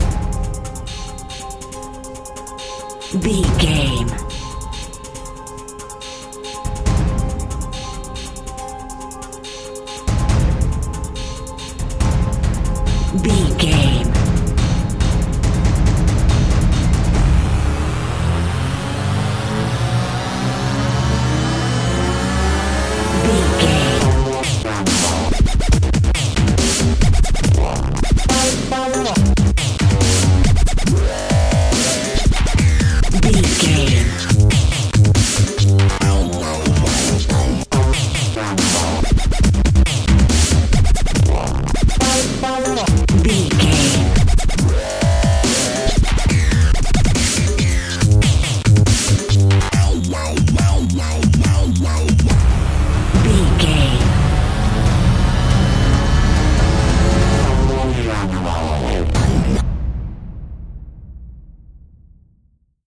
Epic / Action
Aeolian/Minor
percussion
synthesiser
drum machine